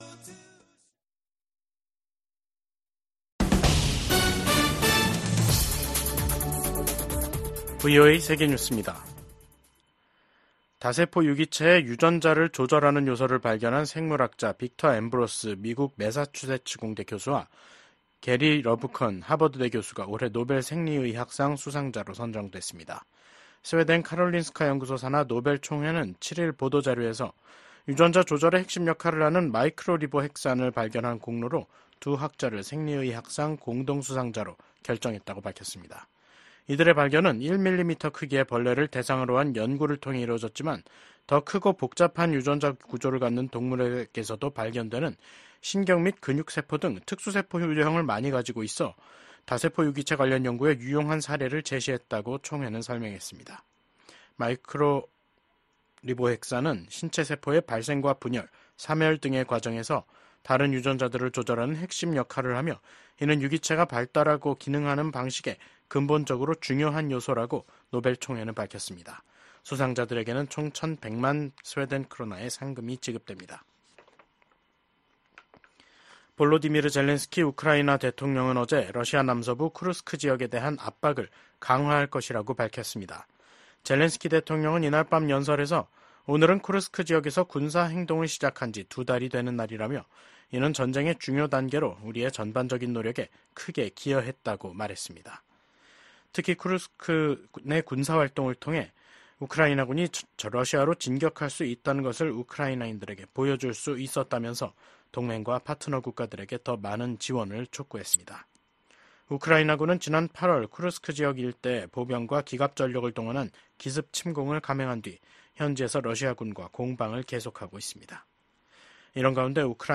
VOA 한국어 간판 뉴스 프로그램 '뉴스 투데이', 2024년 10월 7일 3부 방송입니다.